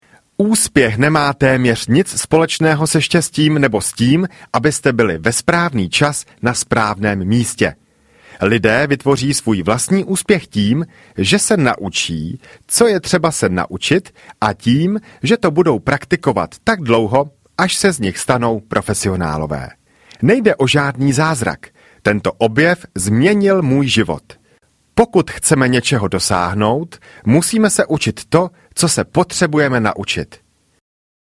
Techniky rychlého učení audiokniha
Ukázka z knihy